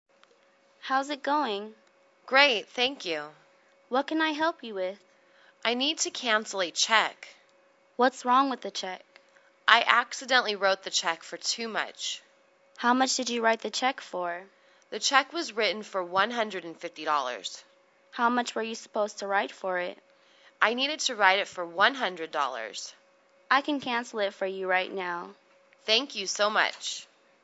在线英语听力室银行英语对话-Canceling a Check(3)的听力文件下载,英语情景对话-银行-在线英语听力室